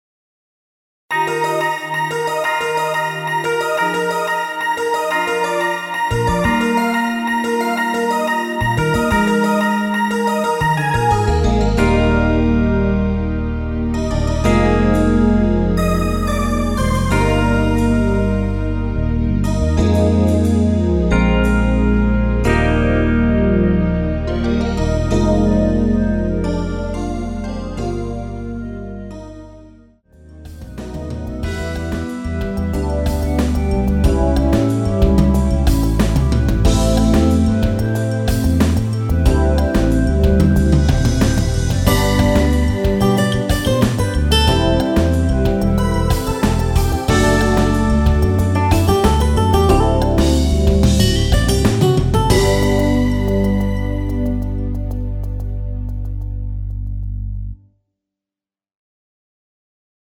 엔딩이 페이드아웃이라 노래하기 편하게 엔딩부분을 다시 만들었습니다(미리듣기 참조하세요)
원키에서(-2)내린 멜로디 포함된 MR입니다.(미리듣기 확인)
Eb
앞부분30초, 뒷부분30초씩 편집해서 올려 드리고 있습니다.
중간에 음이 끈어지고 다시 나오는 이유는